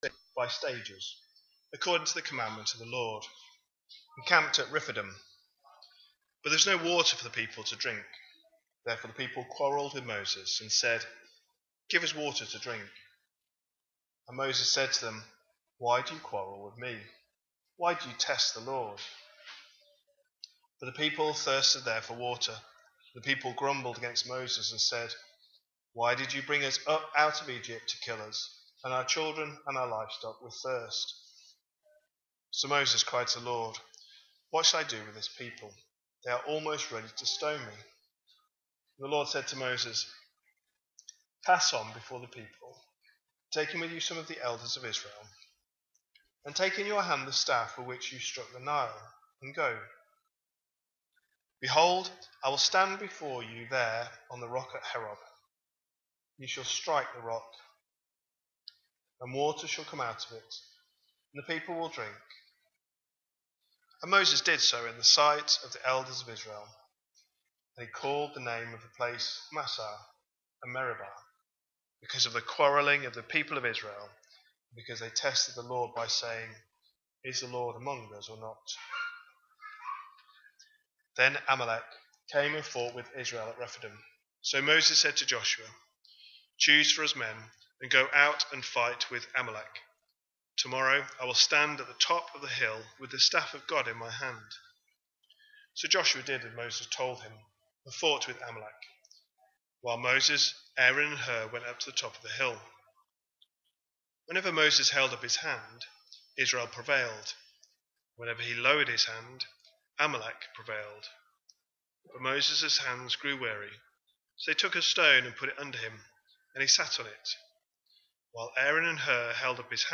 A sermon preached on 24th August, 2025, as part of our Exodus series.